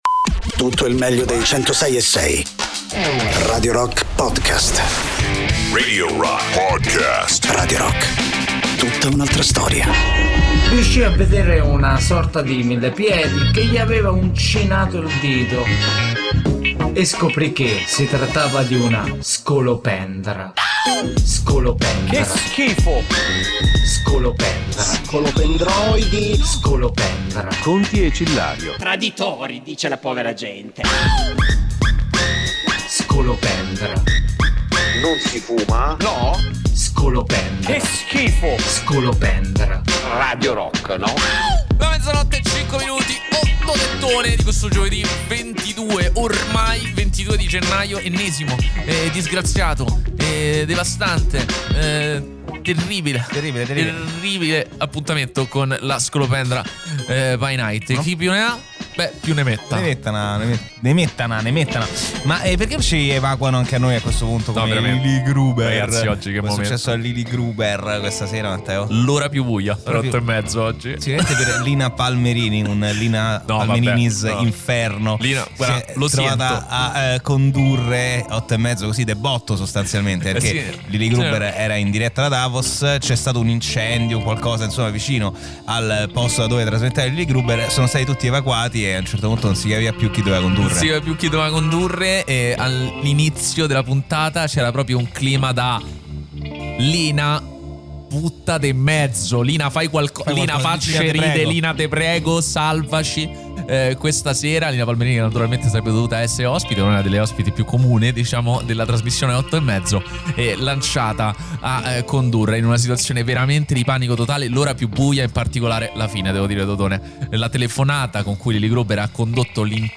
in diretta
sui 106.6 di Radio Rock